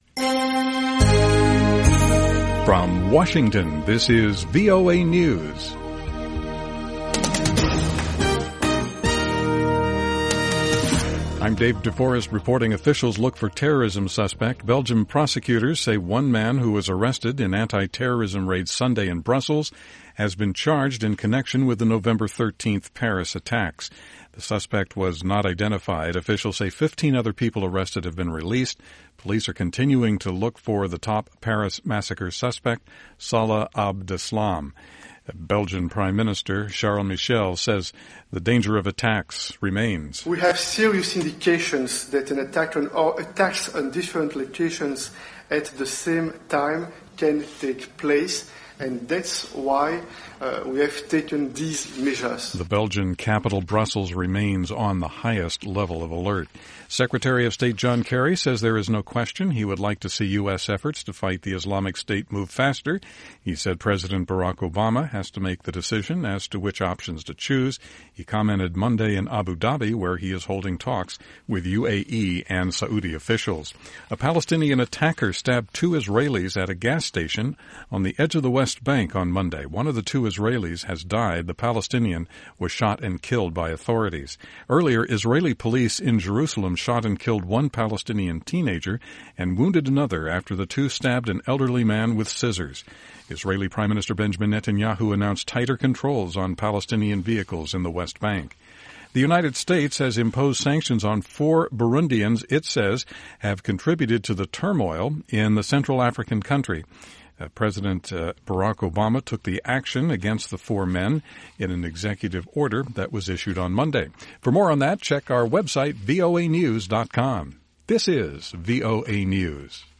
VOA English Newscast 2200 UTC November 23, 2015